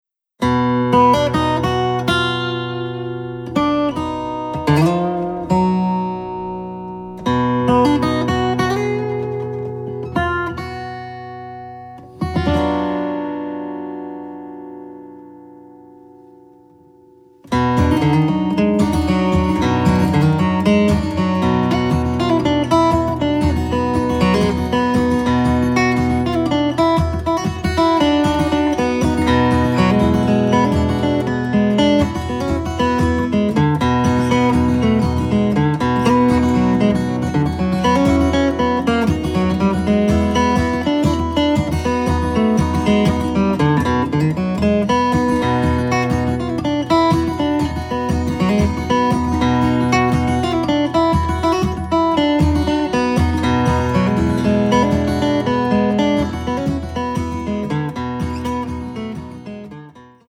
Acoustic Blues Collection